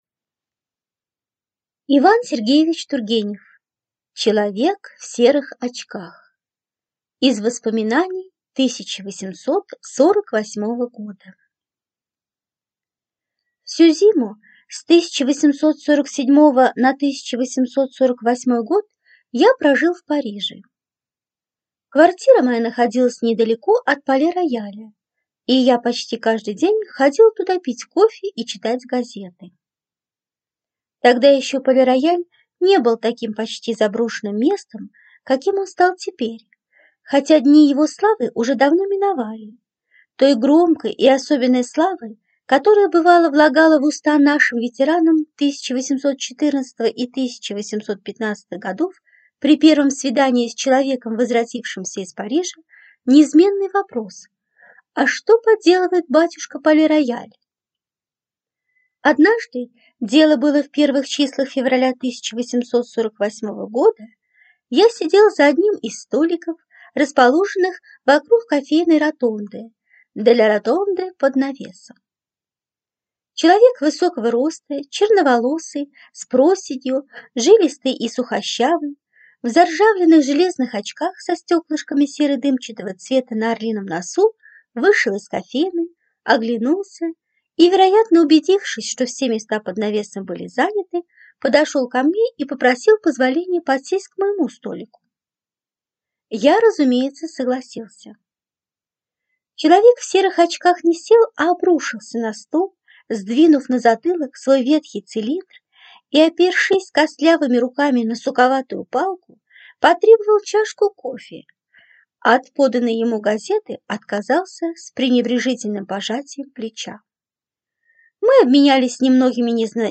Аудиокнига Человек в серых очках | Библиотека аудиокниг